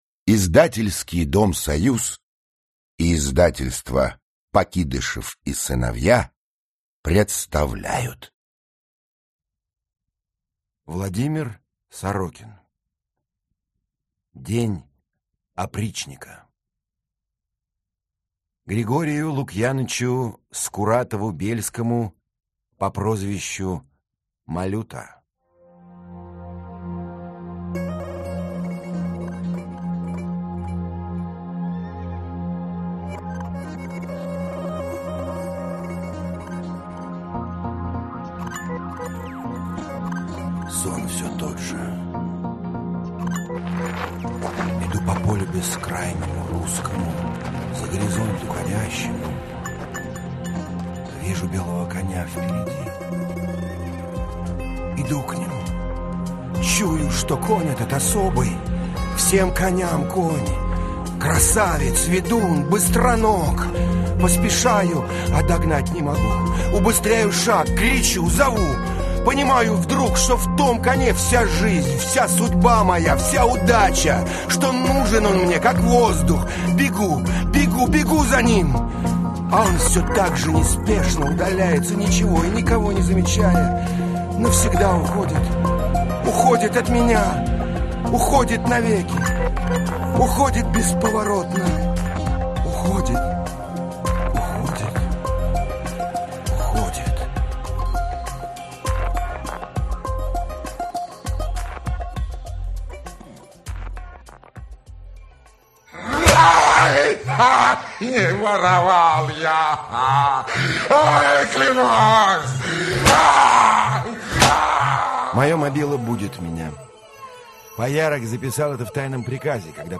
Аудиокнига День опричника (спектакль) | Библиотека аудиокниг
Aудиокнига День опричника (спектакль) Автор Владимир Сорокин Читает аудиокнигу Владимир Сорокин.